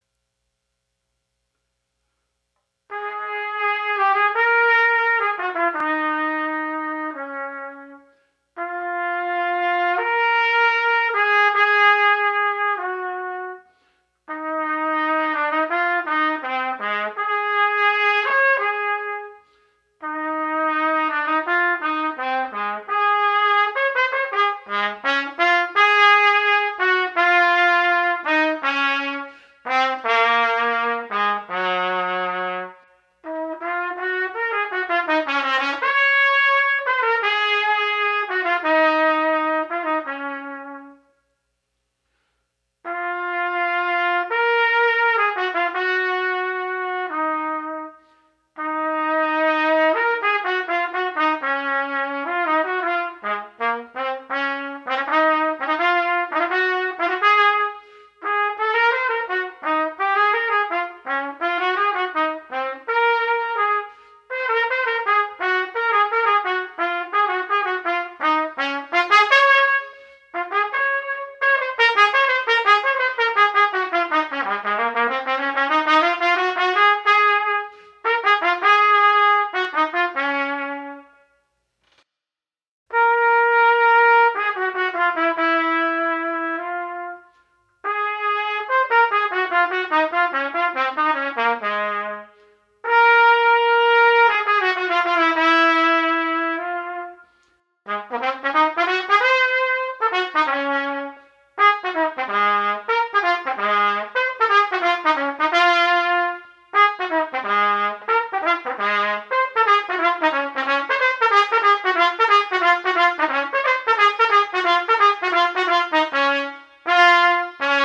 Trumpet
Video #1 – Voisin 11 Studies for Trumpet, No. 1